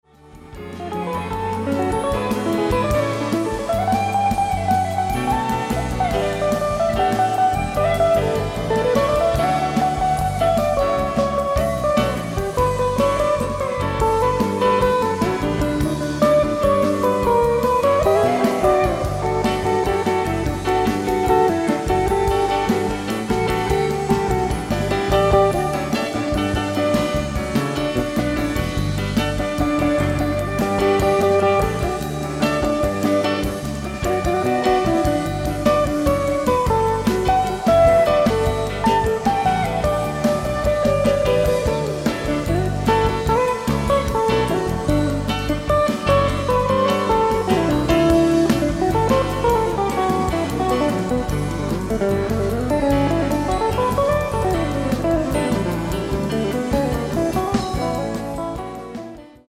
ライブ・アット・ノーマン、オークランド、USA 07/22/1982
※試聴用に実際より音質を落としています。